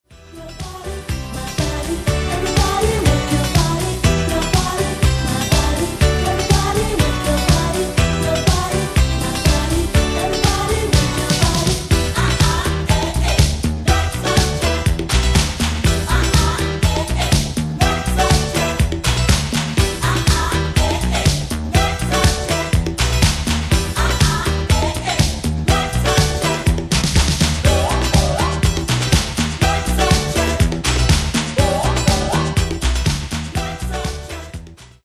[RE-MIX VERSION]